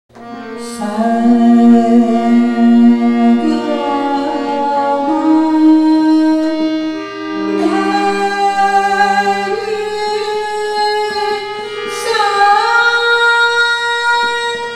ThaatKafi
ArohaS g M D n S’
Bageshree (Aroha)